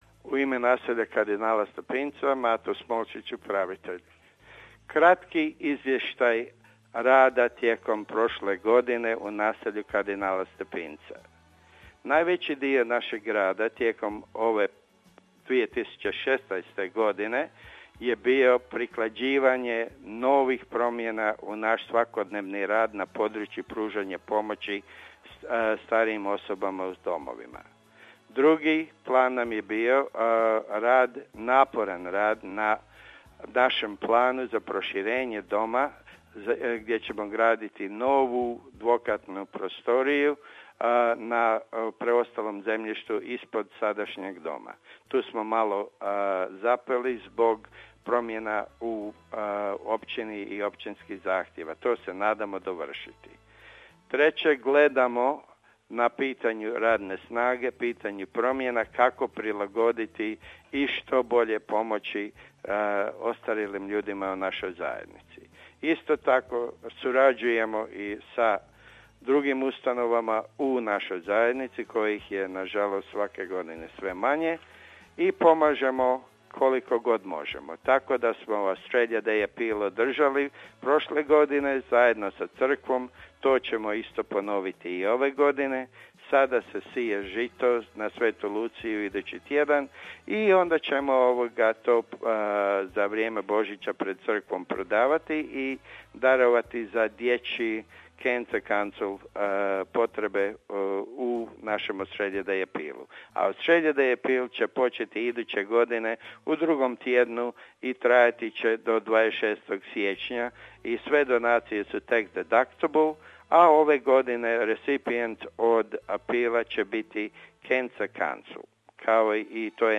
O godini za nama i planovima za 2017. za naš program govore čelnici hrvatskih udruga i organizacija u Australiji.